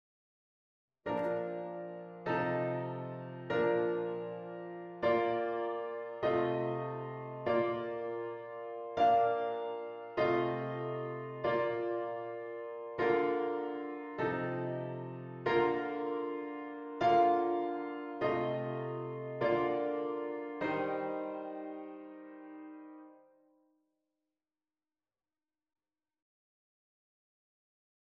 V4/3 als wisselakkoord tussen twee identieke liggingen van I